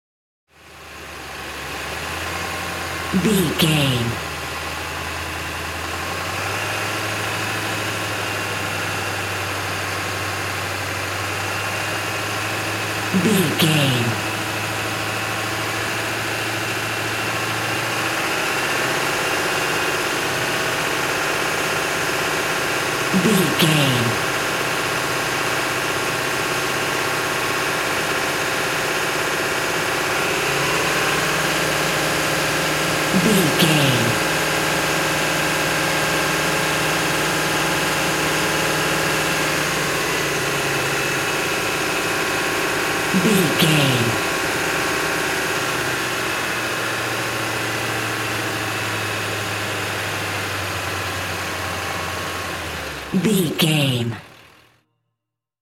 Ambulance Ext Diesel Engine Accelerate
Sound Effects
urban
chaotic
emergency